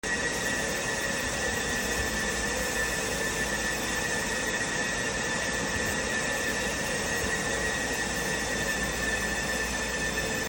Walking through the hot aisle with a decibel meter 🔥📈 Data Center ASMR – fans, servers, and the hum of uptime